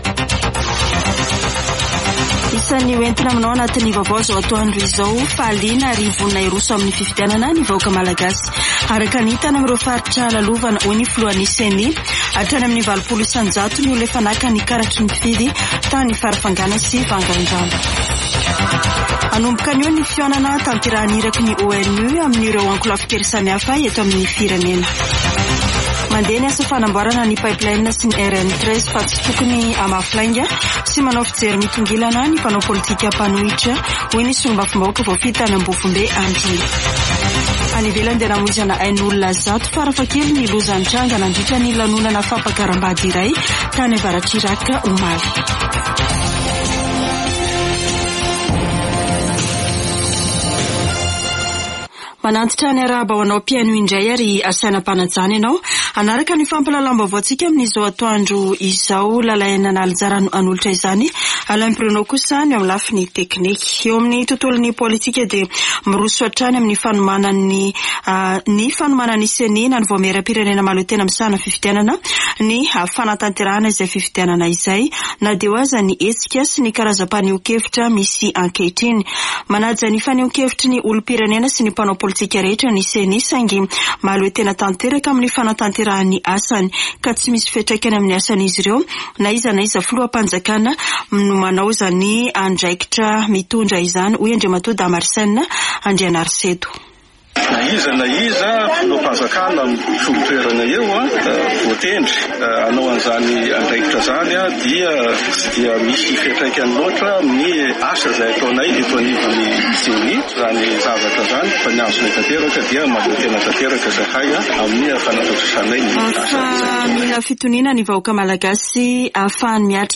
[Vaovao antoandro] Alarobia 27 septambra 2023